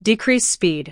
audio_speed_down.wav